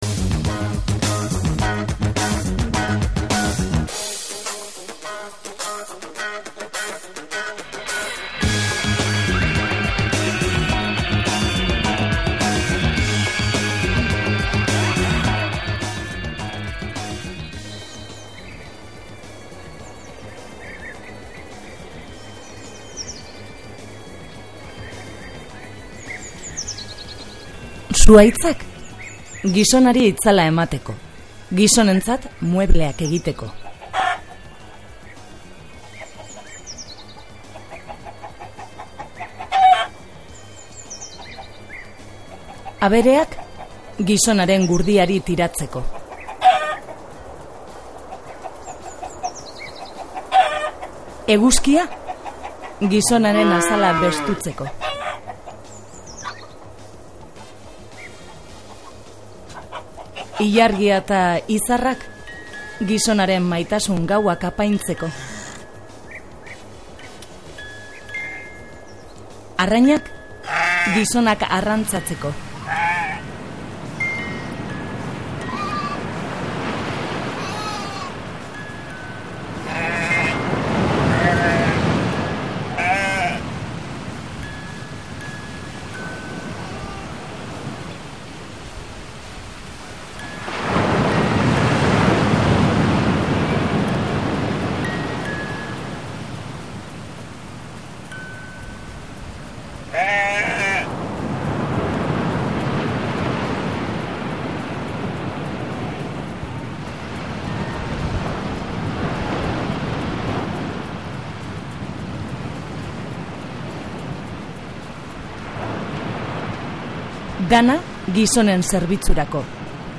Biolinjole gaztearen musika entzun dugu ere. Bestetik aste honetan izango diren kontzertuen errepazua egin dugu, Martha Wainwright, Ama Say, The Cherry Boppers…